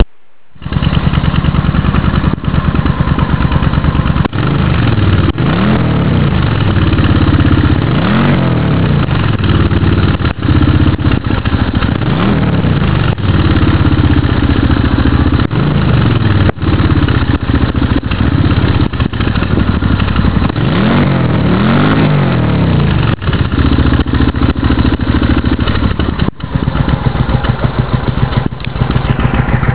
Der Sound ist jetzt sch�n tief und etwas lauter ist sie auch. Und so h�rt sich die "neue" Auspuffanlage nun an: Hier klicken (Wave Sounddatei 118KB) .